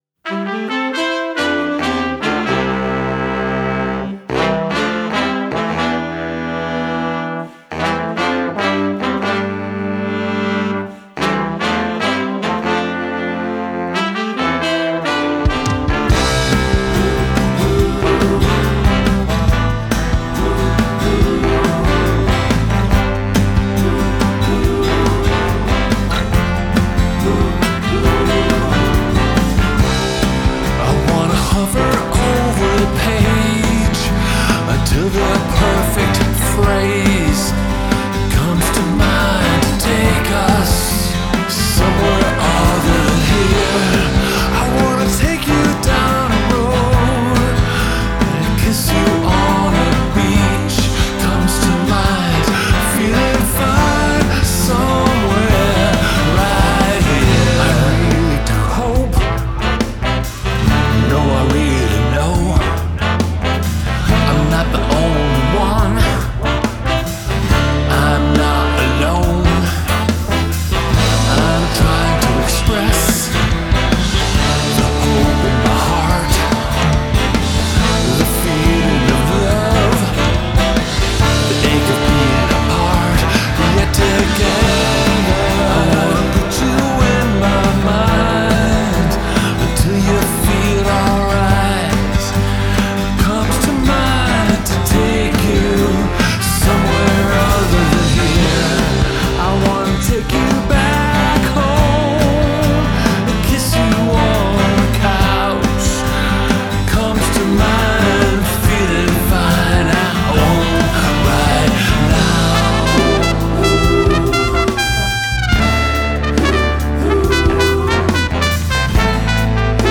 I had this idea of recording 10 or 12 songs live in my barn with a 6 or 7 piece band: trombone, sax, trumpet, guitar, bass, drums and maybe another horn, guitar, or keys...
I wanted to bring these songs to life with a live recording and great players --maybe only overdubbing vocals.
vocals, 6- and 12-string acoustic guitars [DADGAC]
drums, percussion
bass
trumpet
trombone
tenor saxophone
baritone saxophone
backing vocals